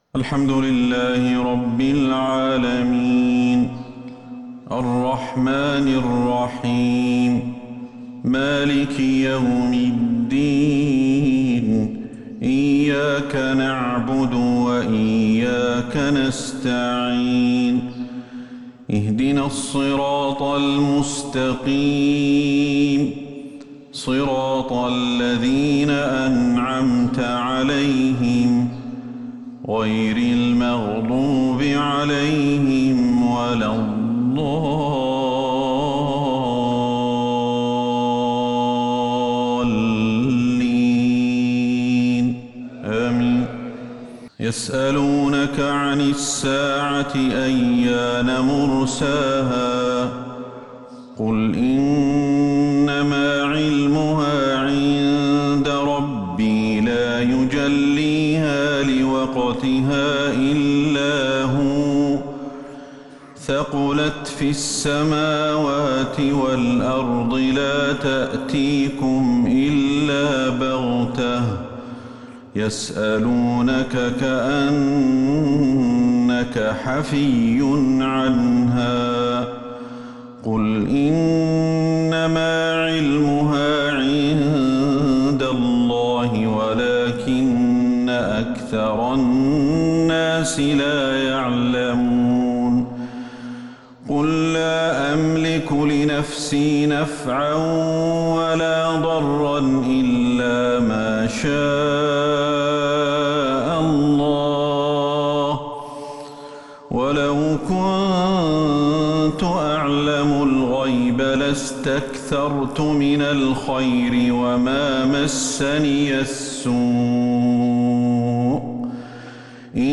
قرَّةُ العينَيْن ، بنقل صلوات وأخبار الحرمين الشريفَيْن ، لـ شهر رجب 1443هـ
ونظرًا لما للحرمين الشريفين من مكانة ، ولما لأئمتهما من تلاوات رائعة وأصوات ندية ؛ فإن هذا العمل يستحق الإشادة...